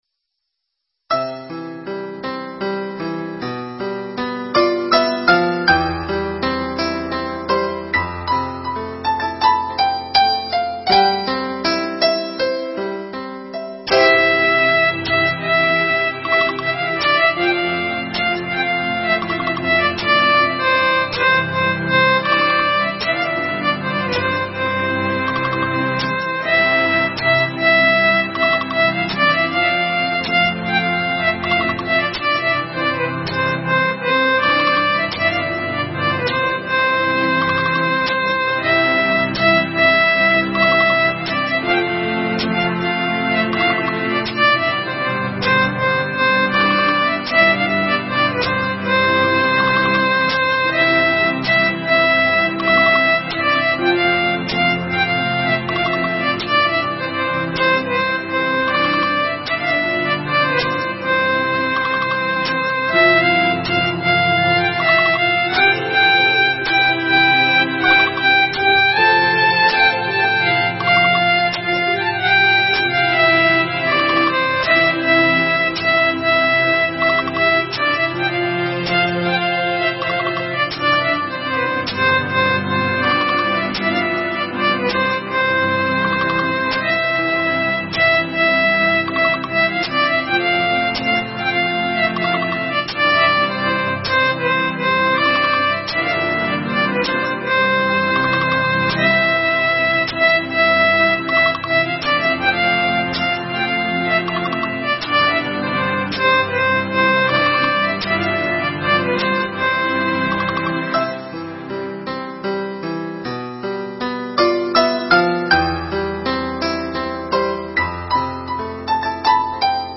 INSTRUMENTAL Section